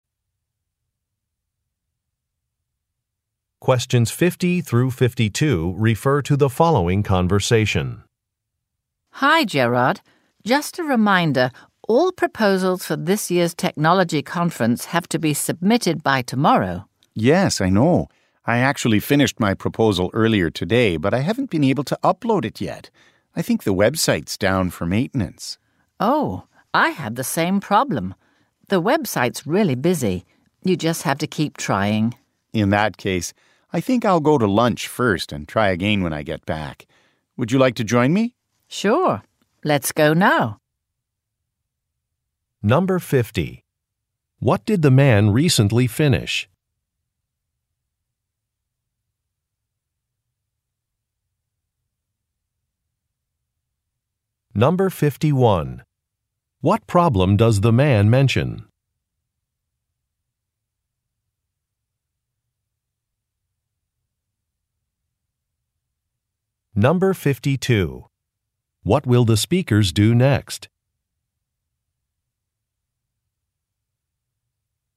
Question 50 - 52 refer to following conversation: